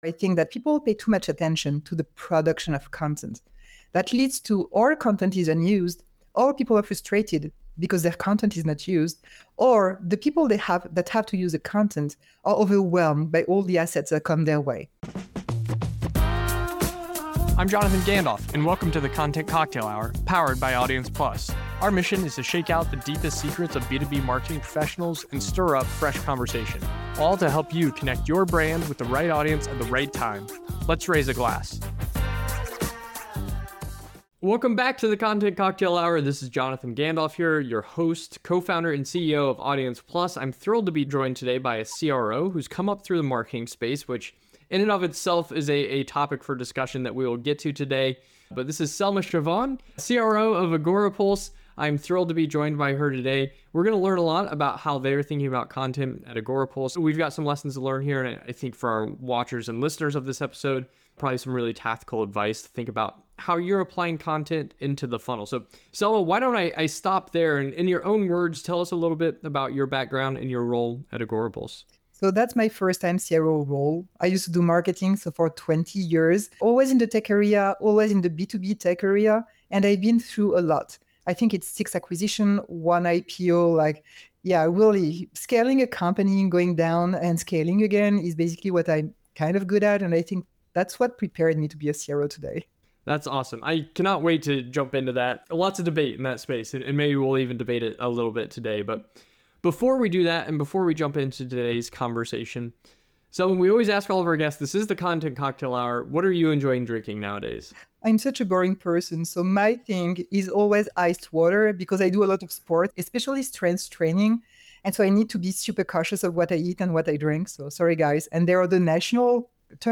1 How Digital-First Studios Are Rewriting the Rules | Live from TellyCast Digital Content Forum 2025 26:58 Play Pause 11d ago 26:58 Play Pause Play later Play later Lists Like Liked 26:58 Factual isn’t standing still - and this panel proves it. Recorded live at the TellyCast Digital Content Forum, this conversation digs into how the factual genre is being rebuilt for a digital-first world.